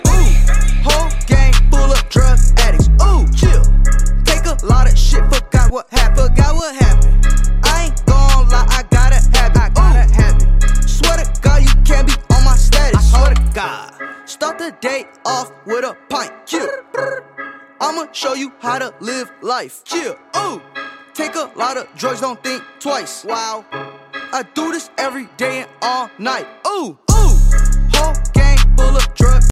ритмичные
Хип-хоп
качающие
Bass